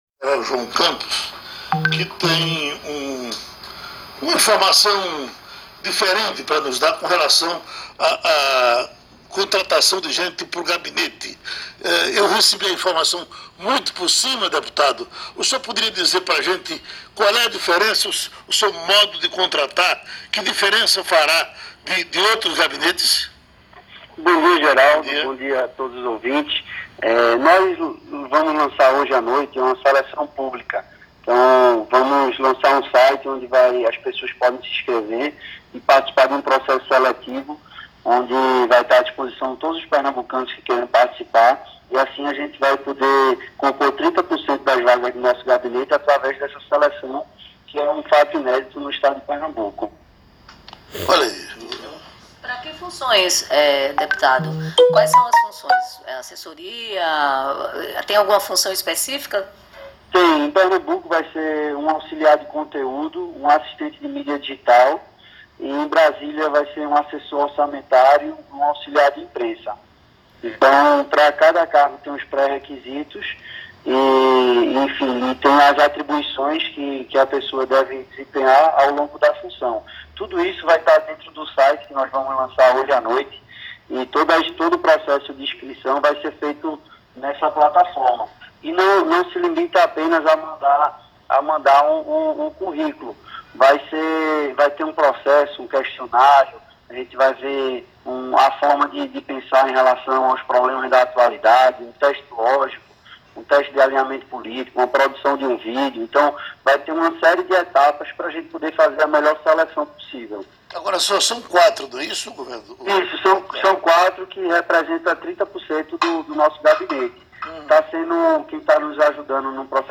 Escute a entrevista de João Campos